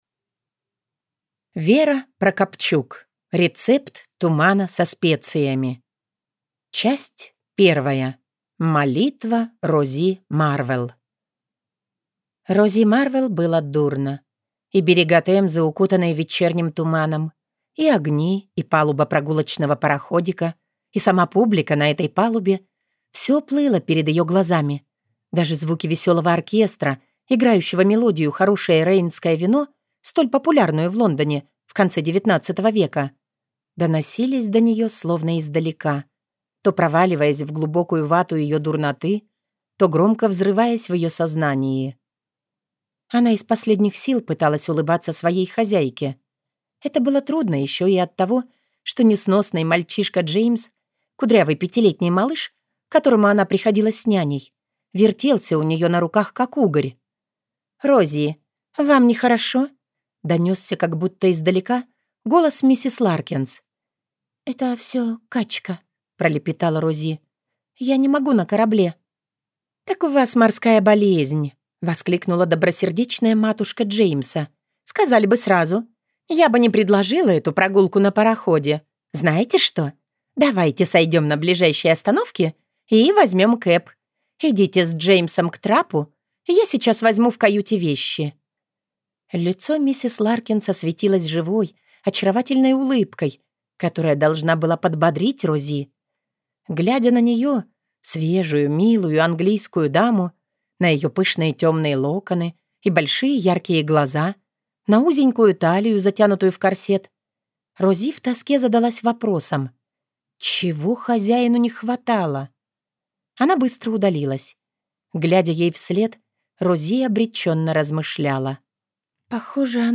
Аудиокнига Рецепт тумана со специями | Библиотека аудиокниг
Прослушать и бесплатно скачать фрагмент аудиокниги